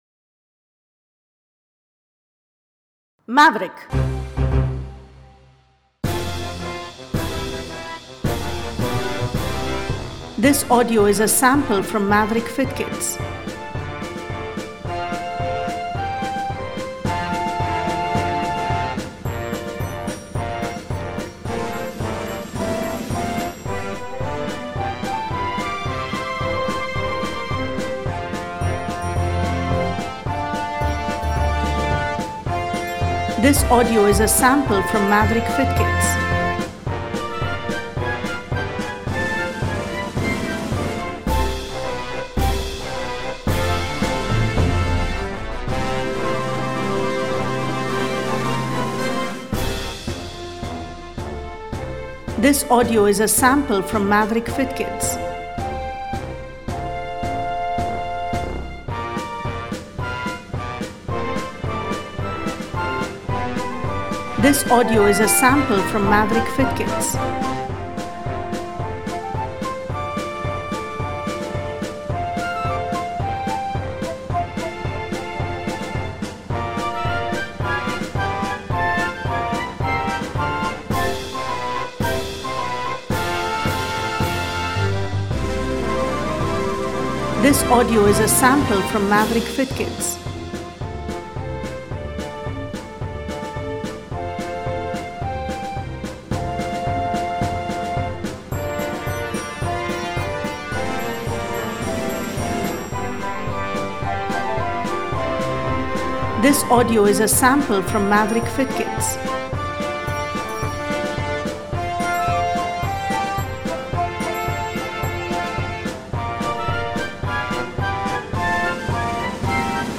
Use music below for a warm up March: